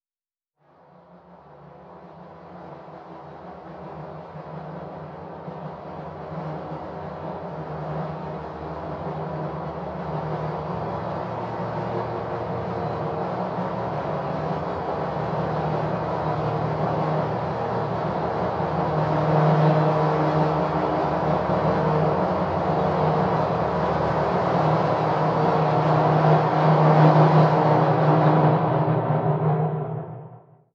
SoundEffectPack / Scifi
blackhole2.wav